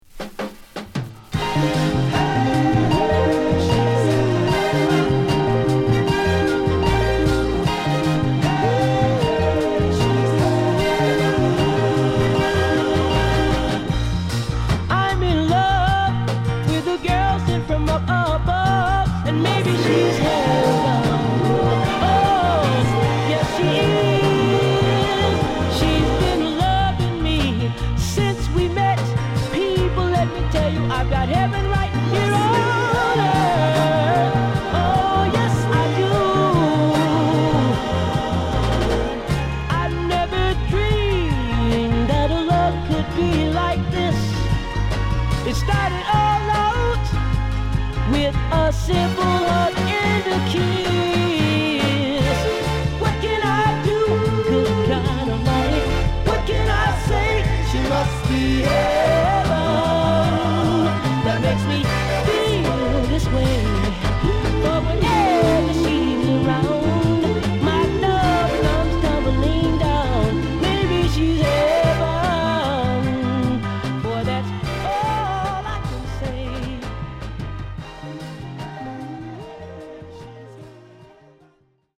心地良いミッドテンポで抜群の高揚感を演出！！